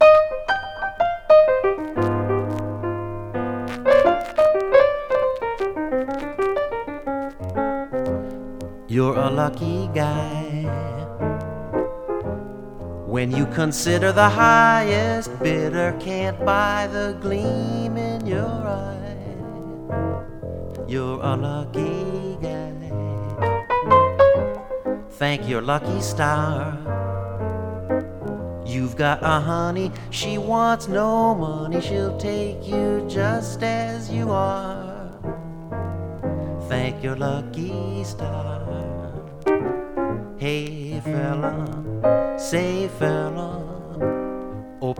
Jazz, Swing, Vocal　USA　12inchレコード　33rpm　Stereo